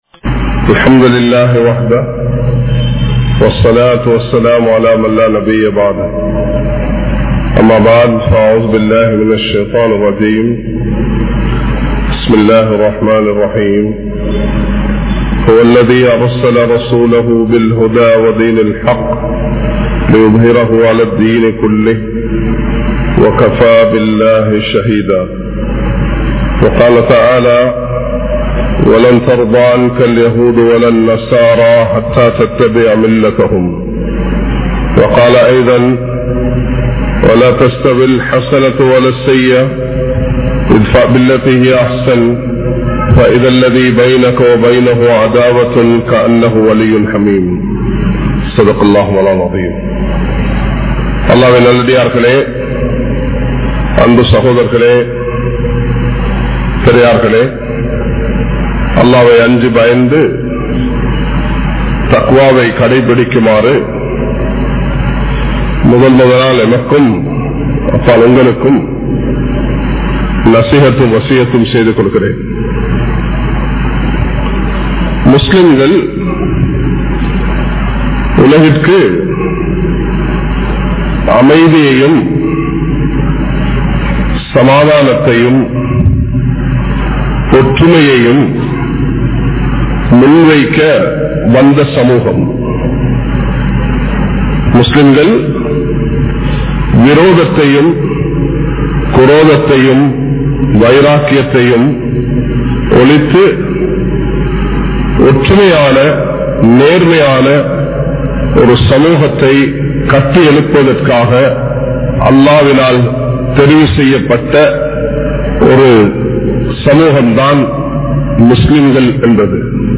Mathangalukku Appaal Manitharhalai Mathiungal(மதங்களுக்கு அப்பால் மனிதர்களை மதியுங்கள்) | Audio Bayans | All Ceylon Muslim Youth Community | Addalaichenai
Majma Ul Khairah Jumua Masjith (Nimal Road)